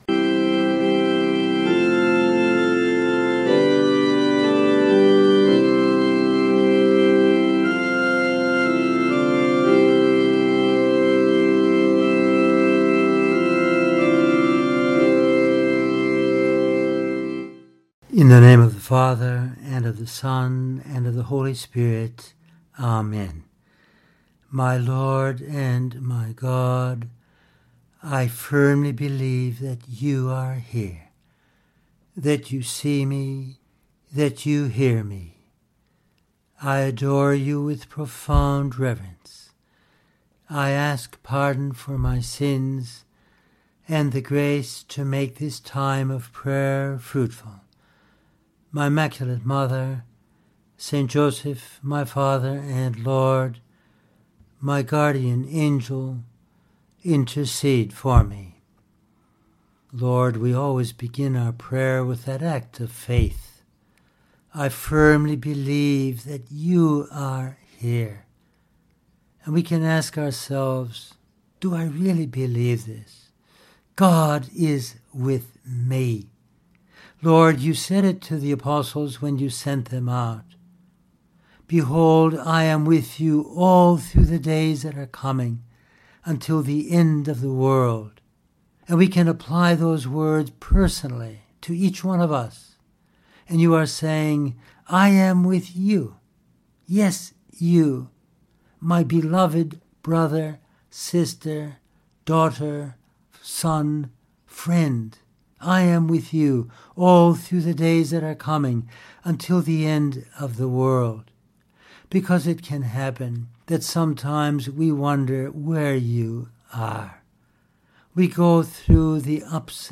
It is then that we need to grow in hope, to trust more in God. In this meditation we pray about this important virtue, drawing on Scripture, the Catechism, Pope Benedict XVI’s encyclical Spe Salvi and St Josemaria’s book The Way to consider: